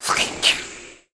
sound / monster2 / snakeman_bow / attack_1.wav
attack_1.wav